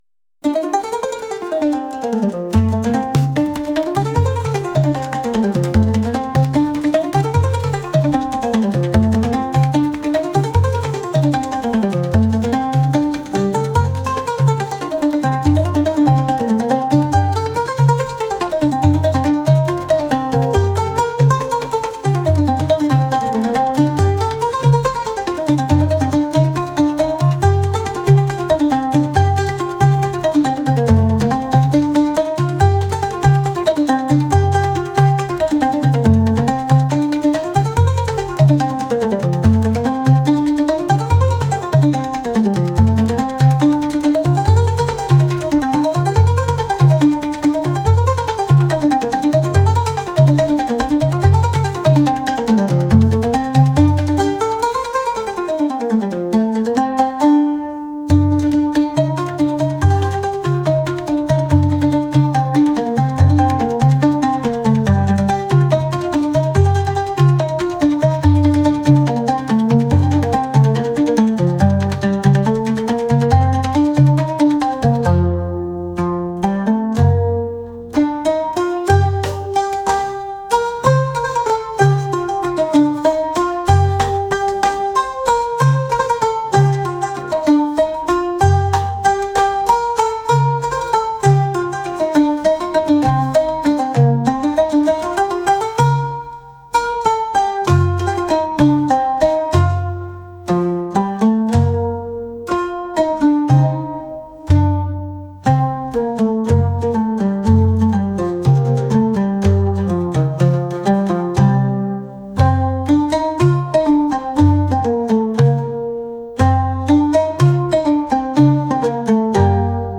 traditional | world